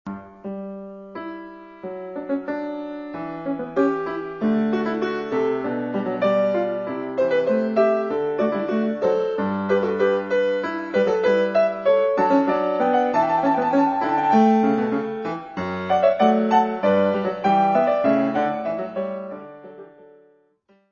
Piano
Área:  Música Clássica